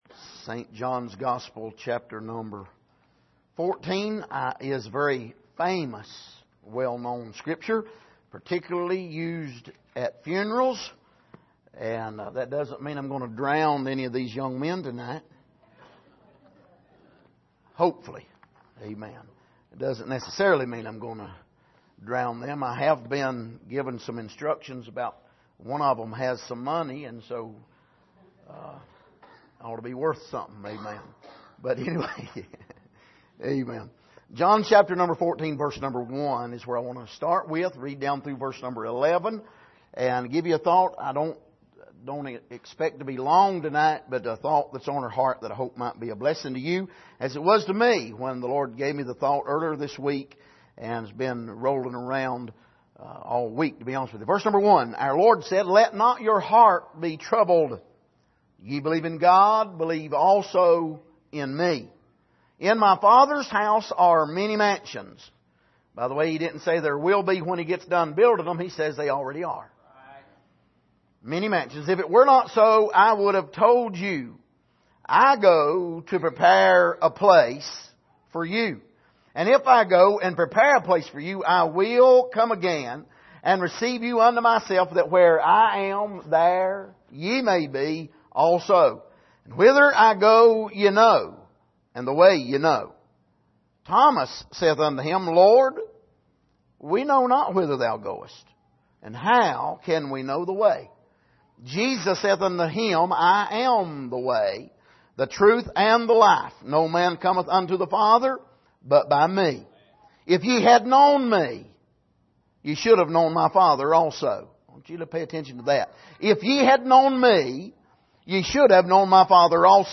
John 14:1-11 Service: Sunday Evening Have You Seen The Father By Seeing Christ?